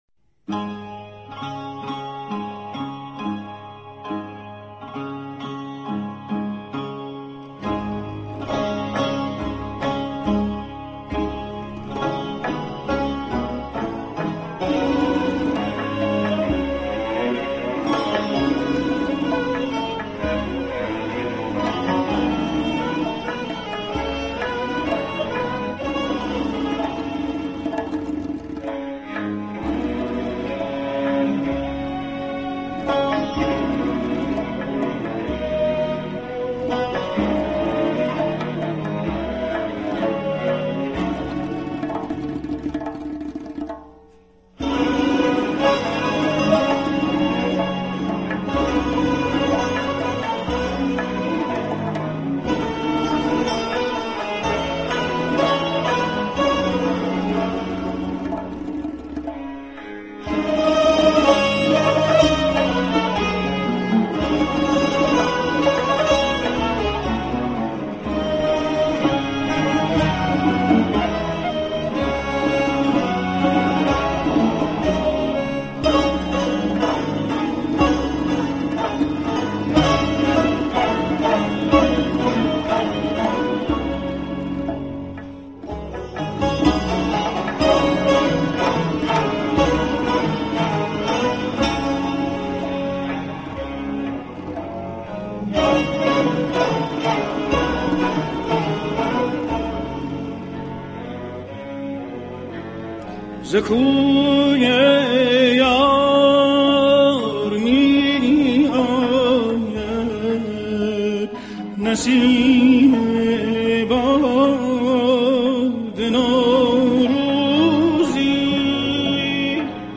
دستگاه: آواز ابوعطا